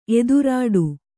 ♪ edurāḍu